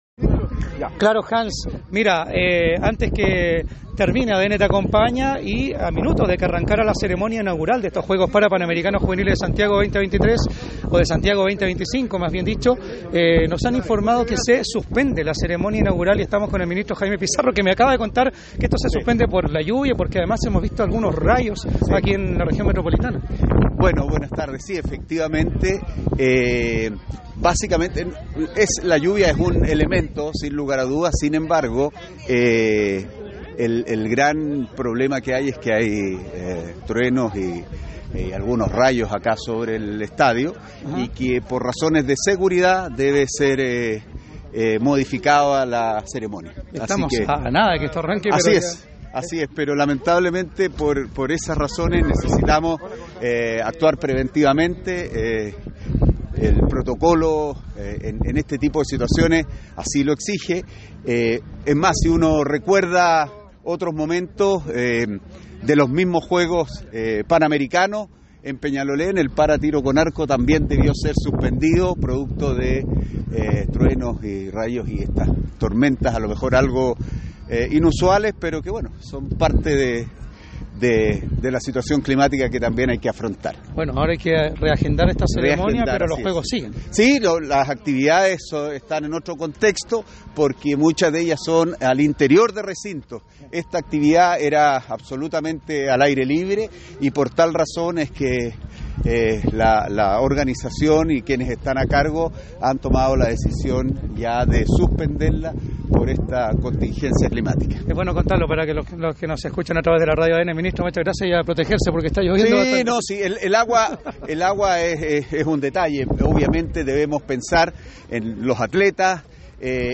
En conversación con ADN Deportes, el Ministro del Deporte, Jaime Pizarro, justificó la medida.